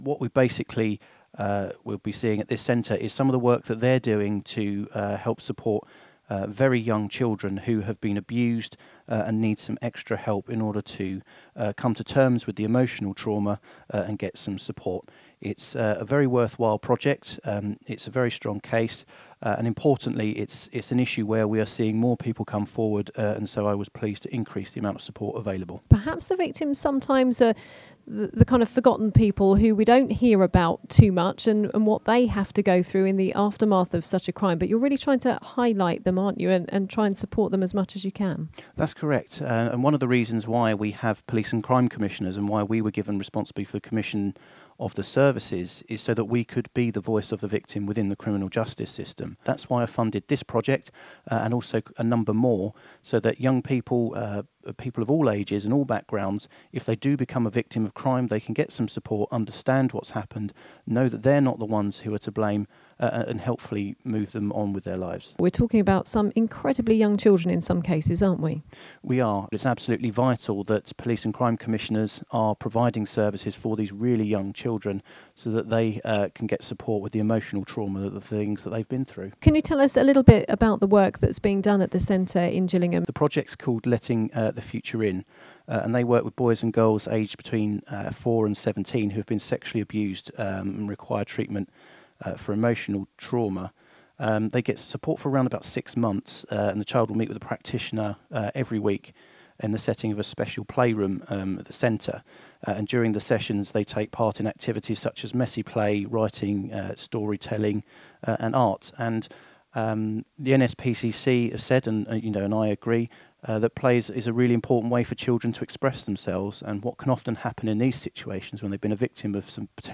LISTEN: Matthew Scott, Kent Police and Crime Commissioner - 11/10/17